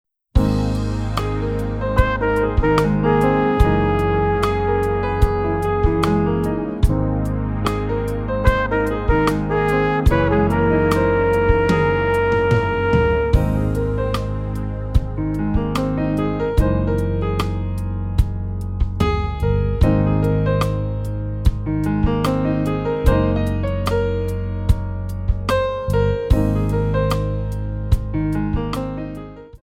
Modern / Contemporary
4 bar intro
R&B soulful ballad